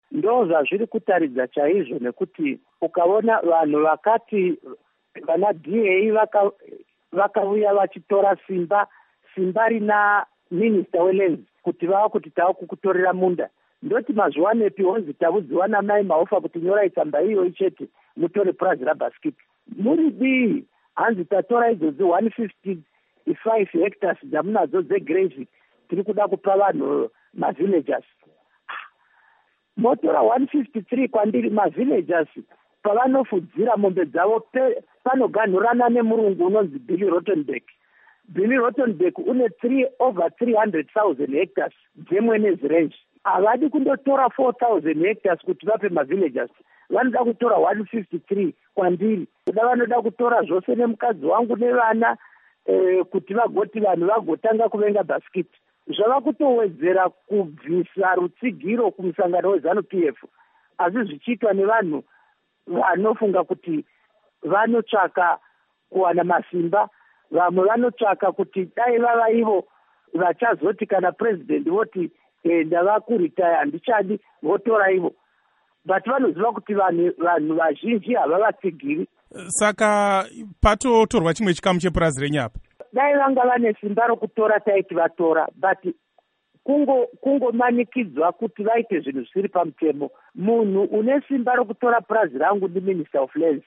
Hurukuro naVaKudakwashe Bhasikiti